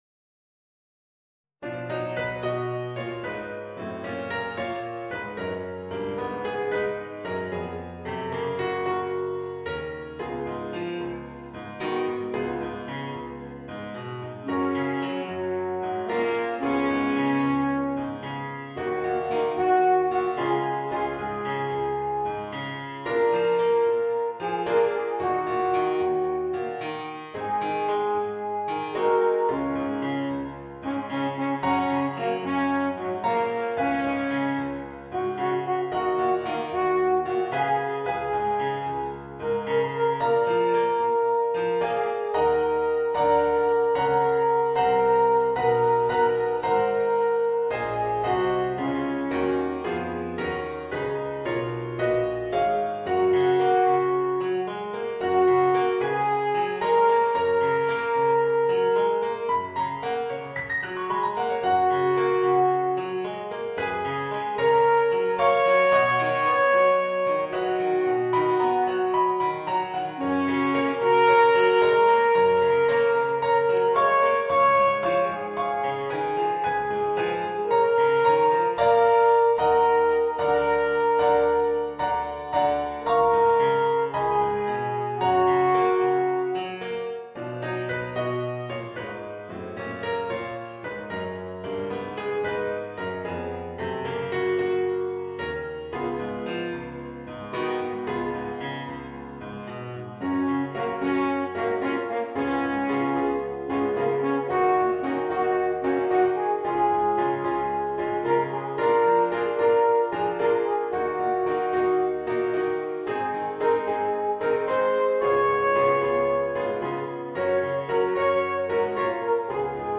Voicing: Alphorn w/ Audio